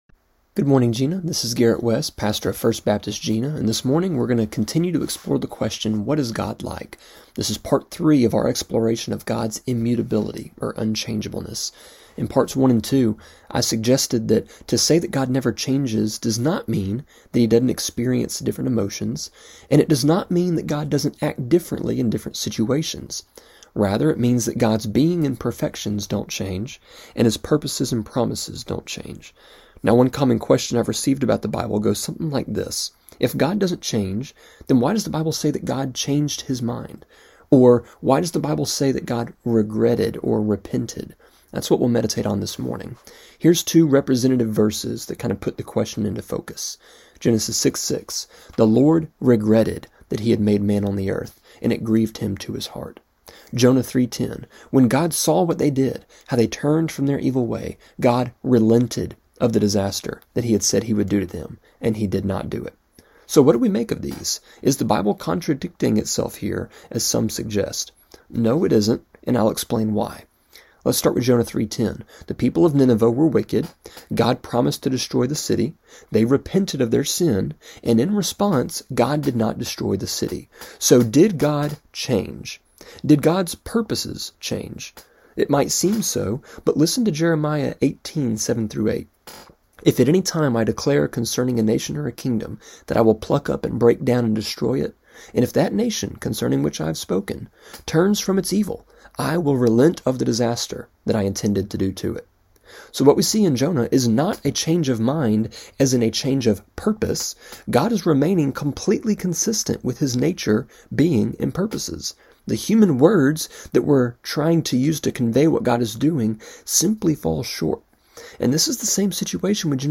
A three minute (-ish) devotion that airs Monday through Friday on KJNA just after 7am.